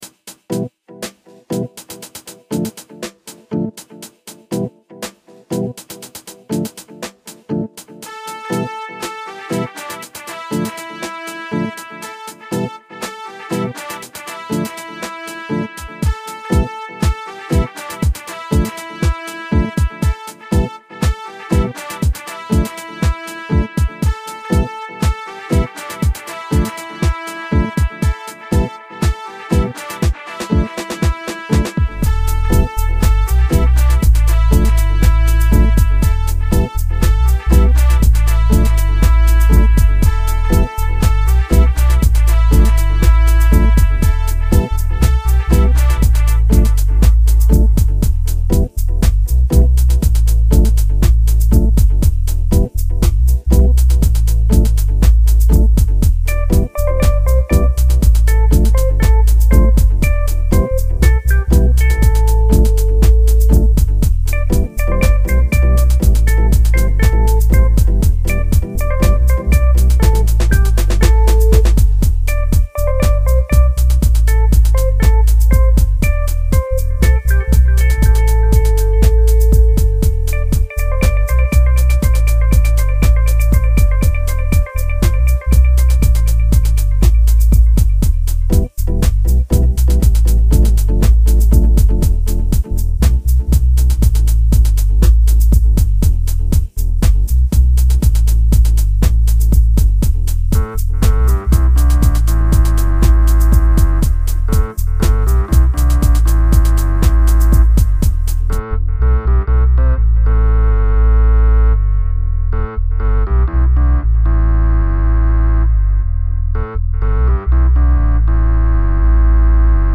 Steppa dub tune made with Reason and Consciousness.